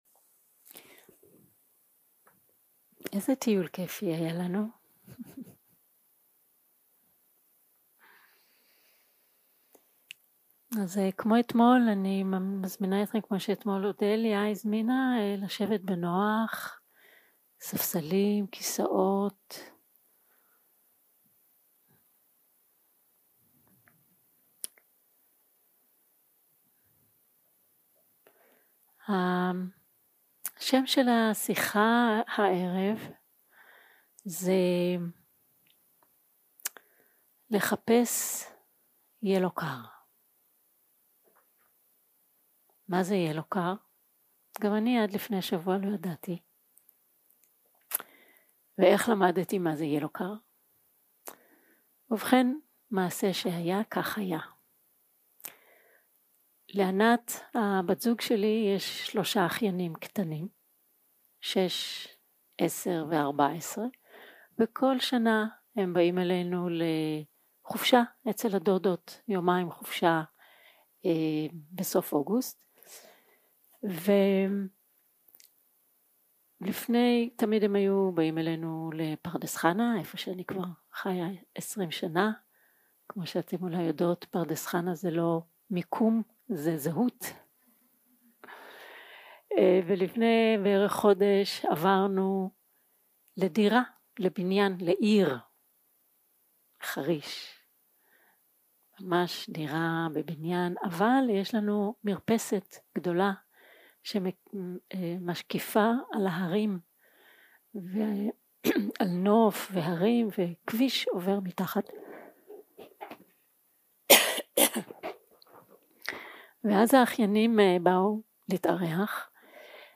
יום 3 - הקלטה 7 - ערב - שיחת דהרמה - לחפש yellow car
סוג ההקלטה: שיחות דהרמה